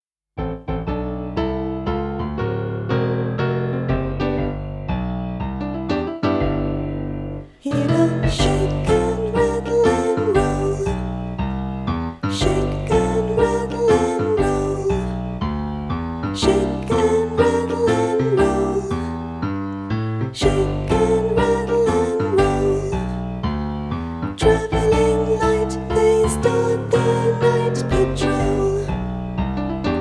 The songs are catchy and create an excellent performance.